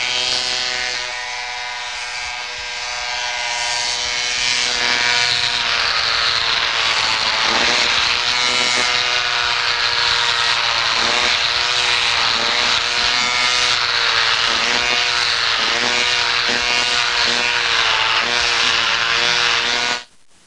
Hedge Trimmer Sound Effect
Download a high-quality hedge trimmer sound effect.
hedge-trimmer.mp3